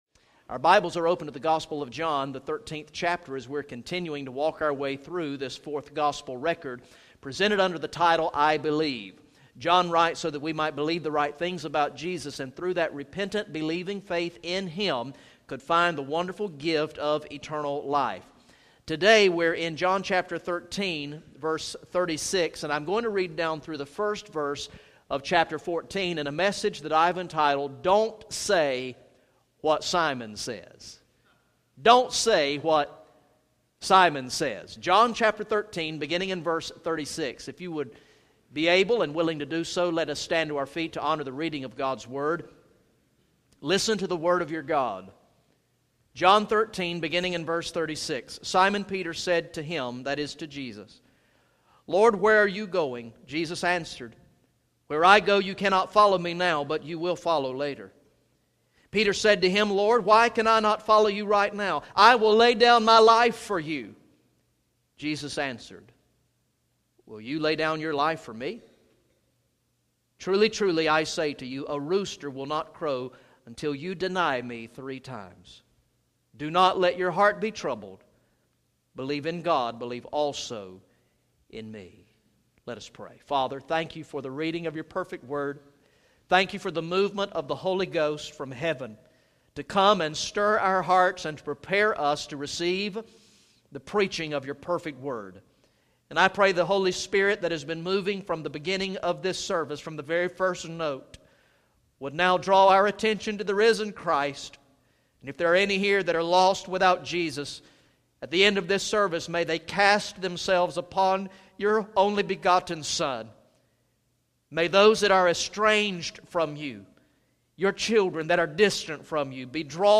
Message #49 from the sermon series through the gospel of John entitled "I Believe" Recorded in the morning worship service on Sunday, November 22, 2015